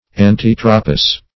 Search Result for " antitropous" : The Collaborative International Dictionary of English v.0.48: Antitropal \An*tit"ro*pal\, Antitropous \An*tit"ro*pous\, a. [Pref. anti- + Gr.